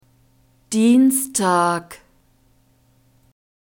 [d] d voiced
vocal cords are vibrating during the articulation   Dienstag